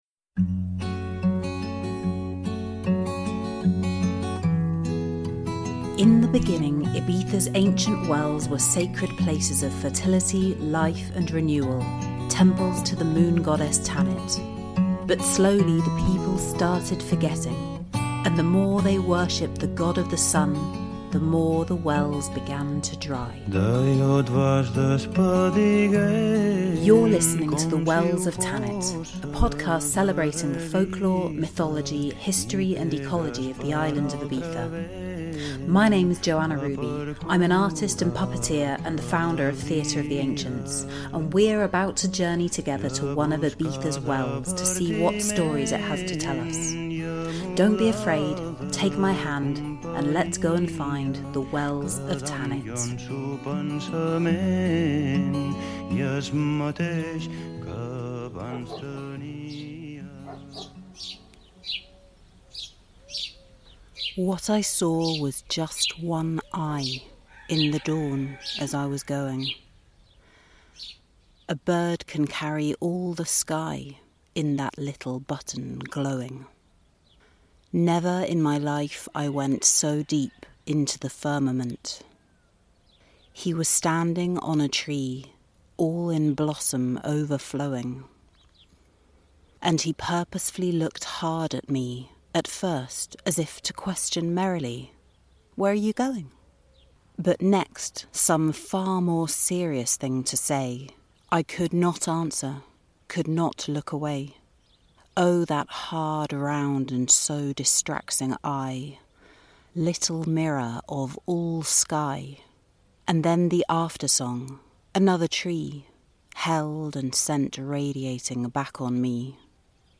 I bring this episode to you from the Pou de Aubarqueta, near San Miguel, a stunningly beautiful and historical well, easily recognised by its curved, egg-shaped form on the roadside.
This episode features birdsong recorded on my phone over the past year in different locations of the North of Ibiza, at different times of day.